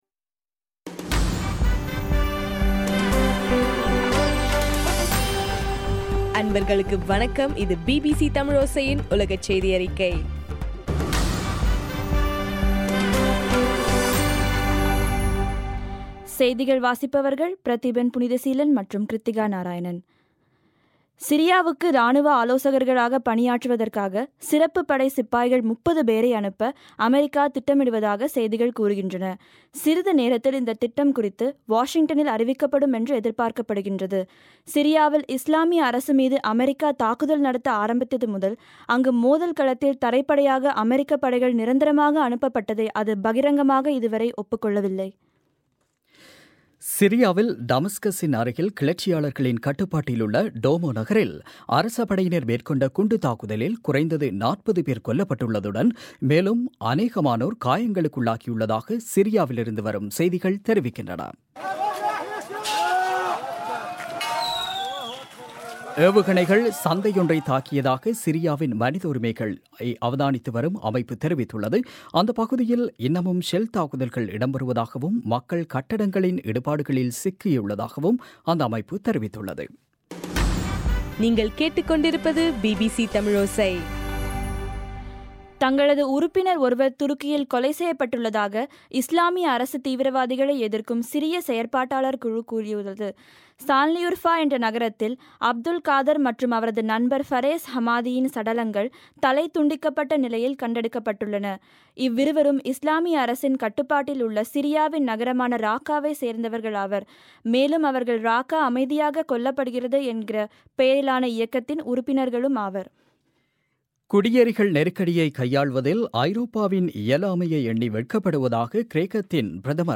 அக்டோபர் 30 பிபிசியின் உலகச் செய்திகள்